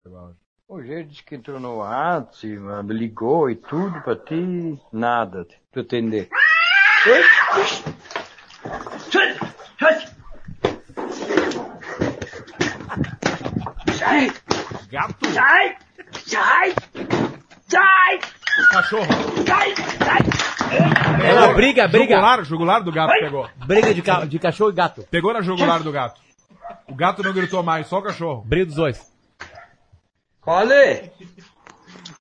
Briga gato e cachorro #47
briga-gato-e-cachorro-47.mp3